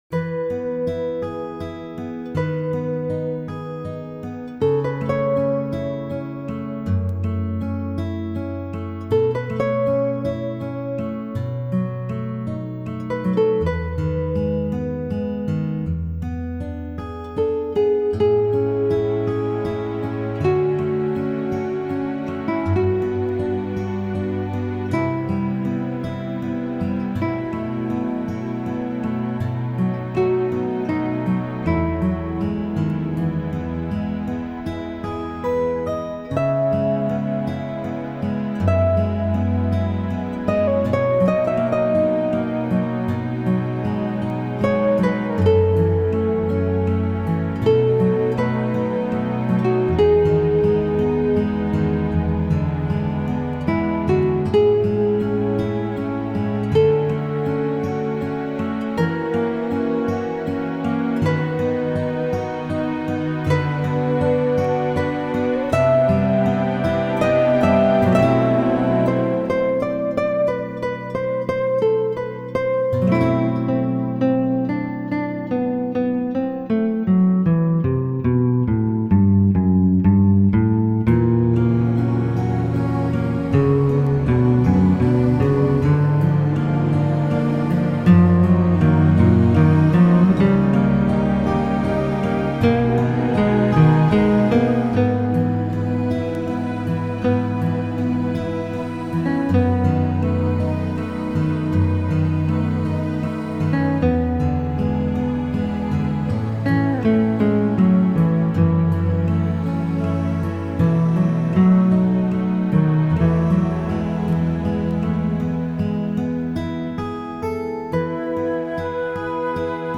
classical guitarist